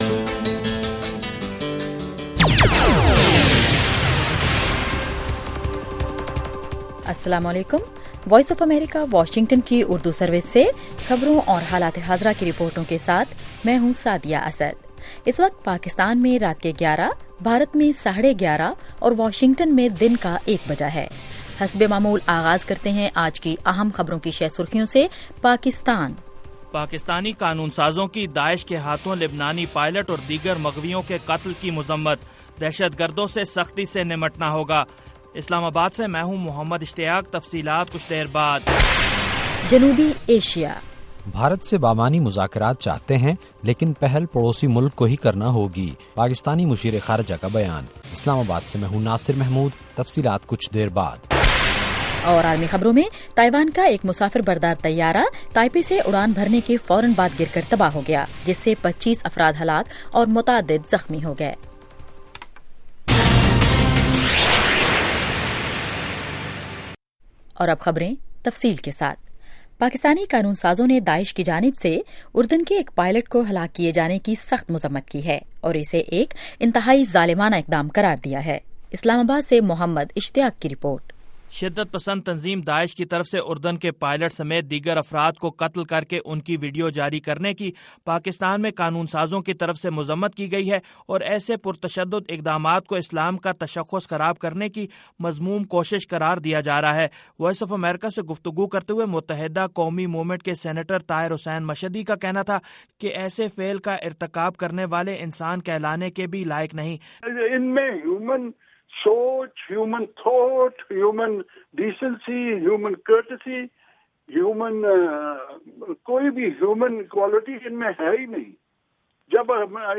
11:00PM اردو نیوز شو